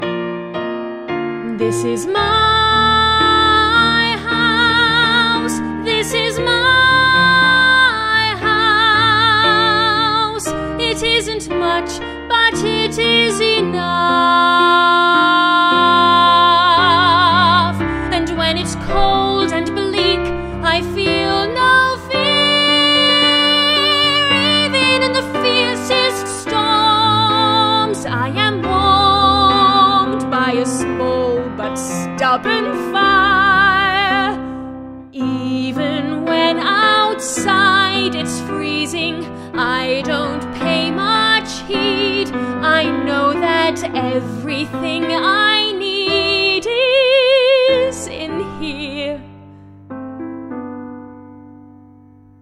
Women
Listen to: (Vocal Support)